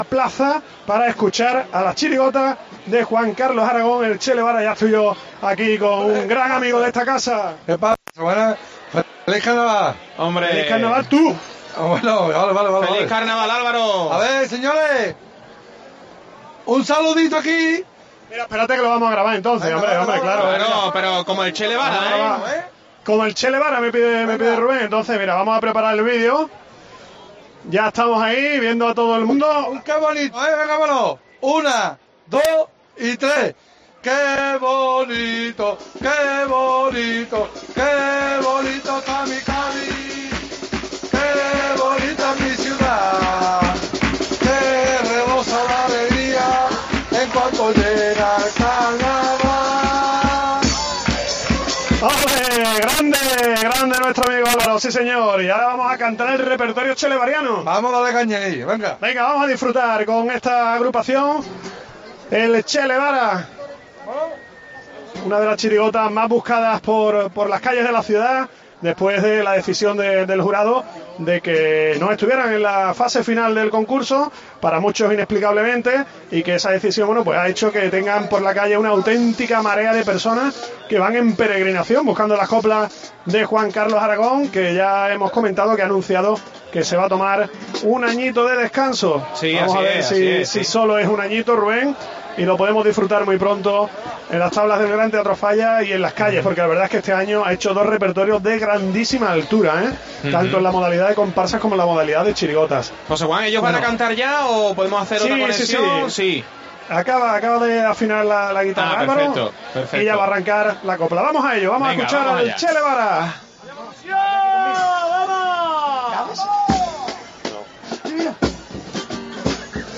AUDIO: COPE contando todo el Carnaval de Cádiz en la calle. Escucha el repertorio de 'Er Chele Vara' en la Plaza de San Juan de Dios